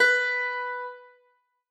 Harpsicord
b4.mp3